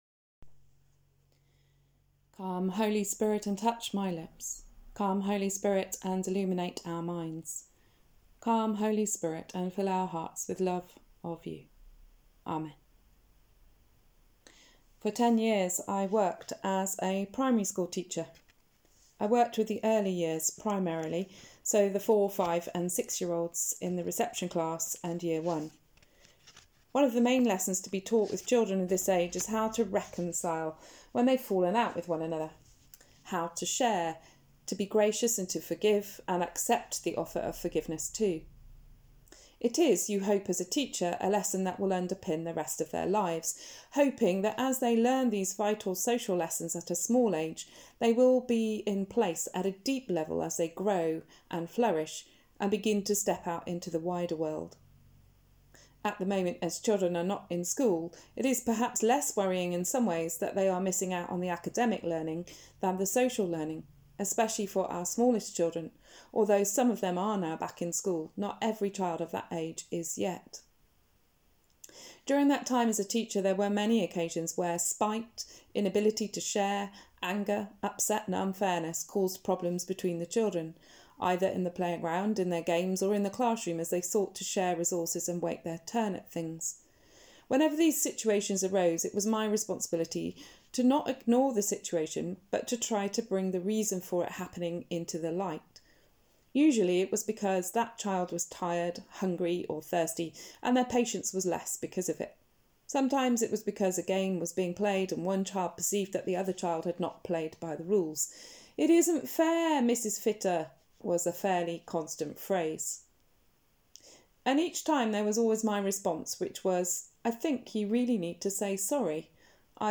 Sermon: Forgiveness That Enables Justice | St Paul + St Stephen Gloucester